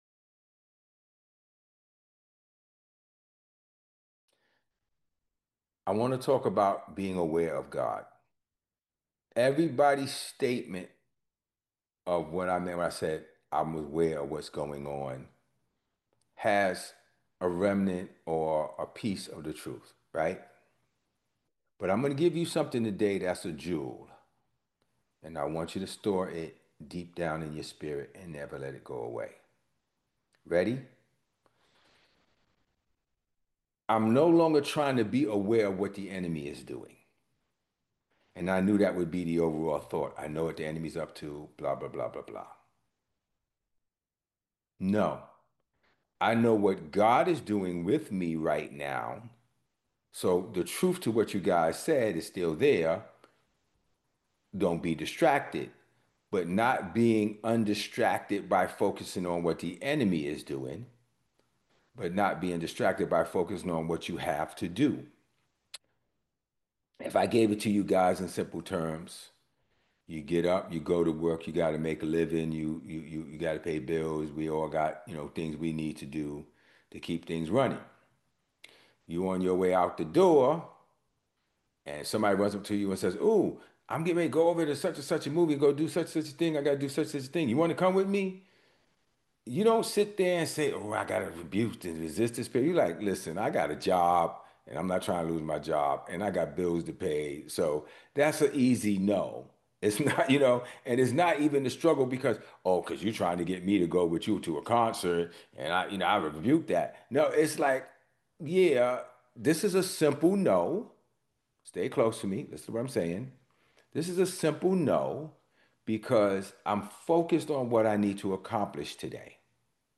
In this week’s message, we learned that we should focus on what God called us to do.